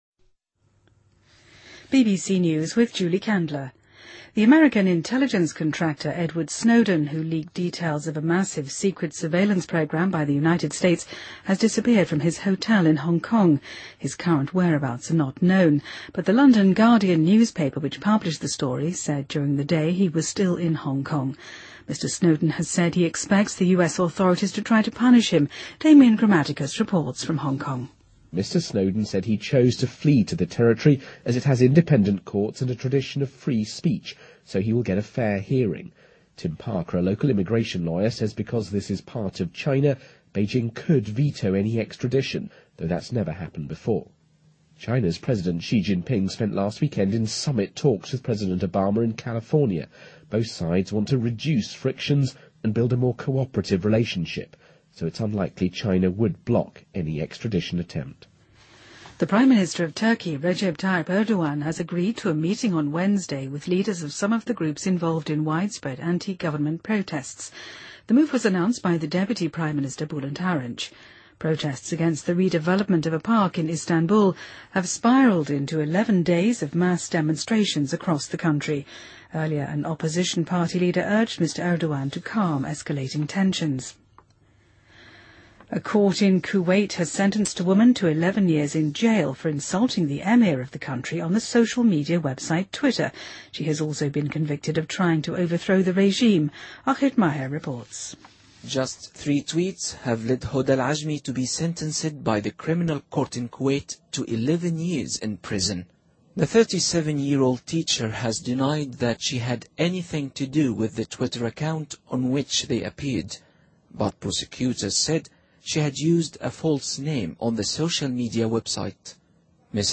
BBC news,2013-06-11